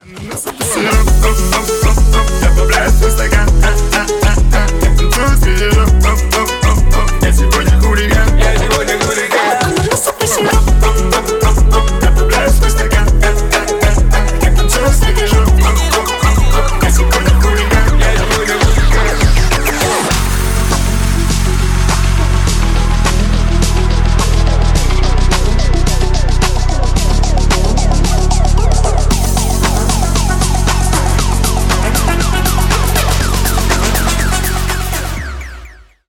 Ремикс # Поп Музыка
весёлые